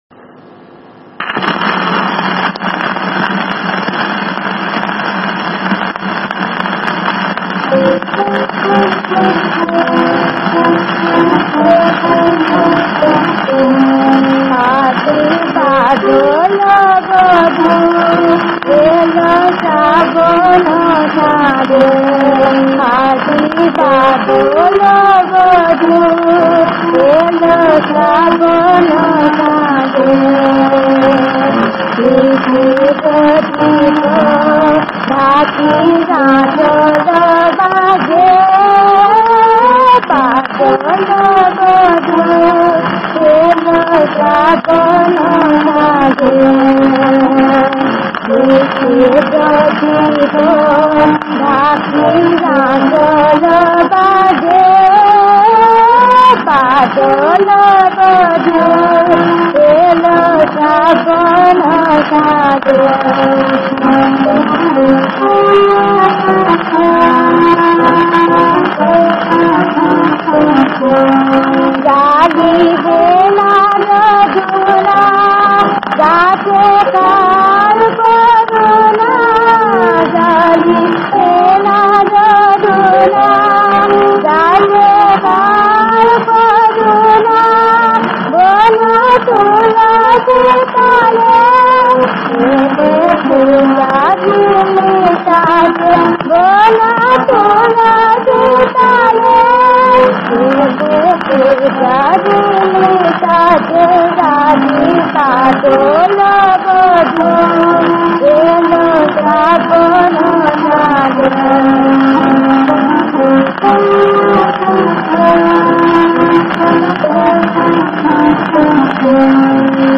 • সুরাঙ্গ: স্বকীয় বৈশিষ্ট্যের গান (আধুনিক)।
• তাল: কাহারবা
• গ্রহস্বর: সা